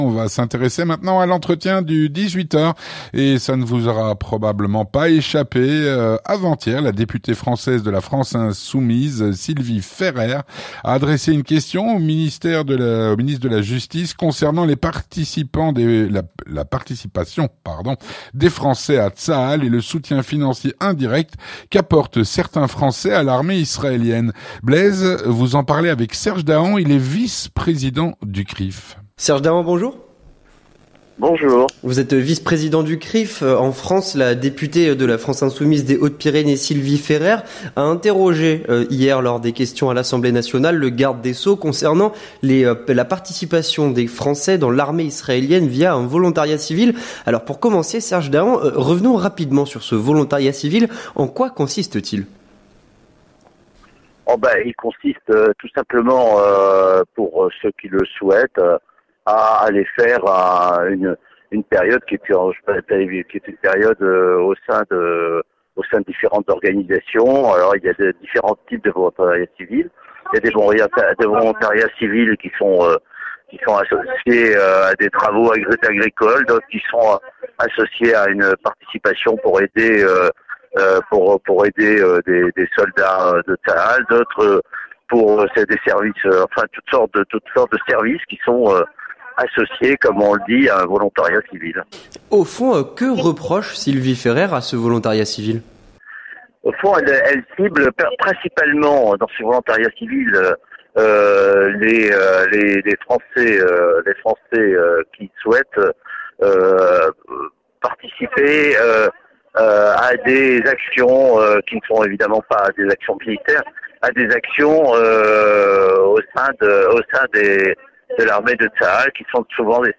3. L'entretien du 18h